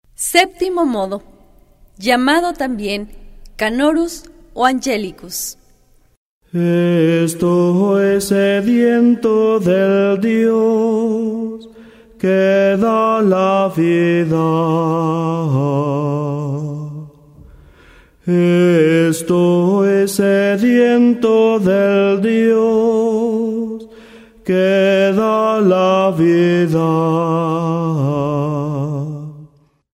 08 Septimo modo gregoriano.